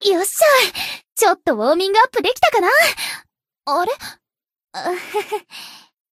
贡献 ） 分类:蔚蓝档案语音 协议:Copyright 您不可以覆盖此文件。
BA_V_Asuna_Battle_Victory_1.ogg